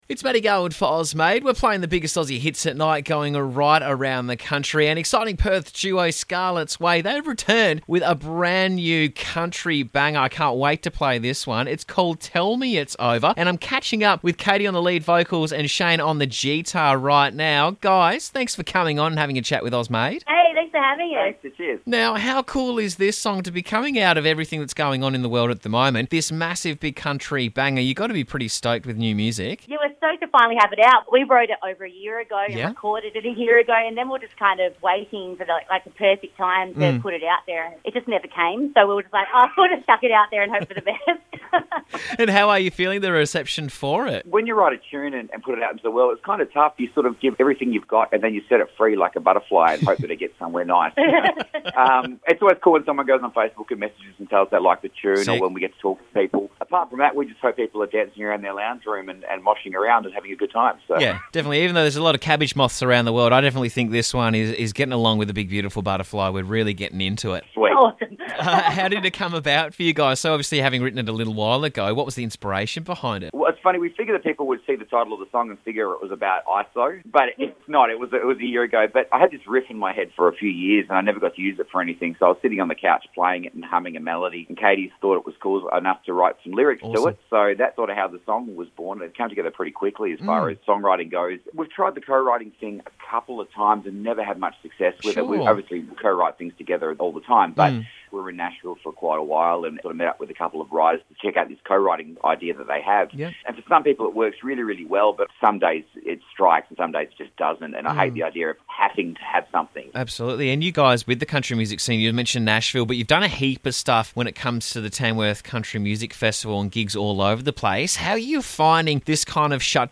Lead Vocals
Guitar
southern, roots-inspired music